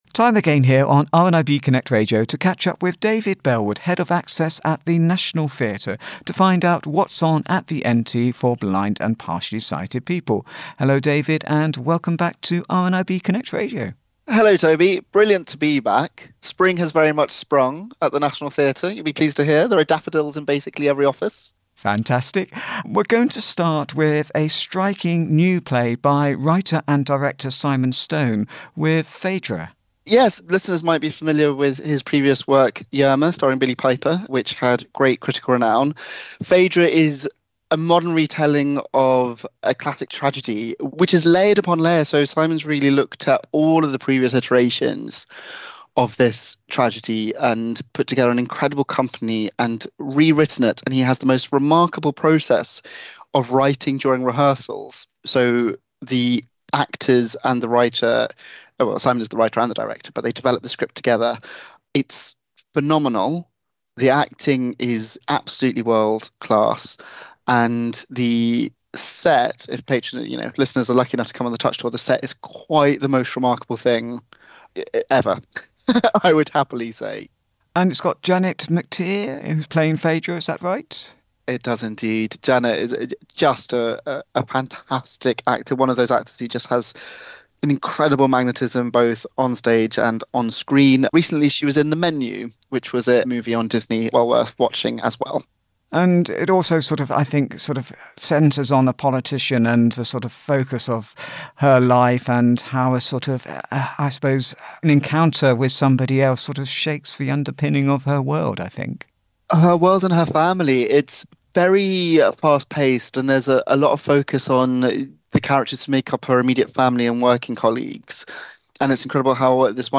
to chat about a few up-coming audio described shows at the National Theatre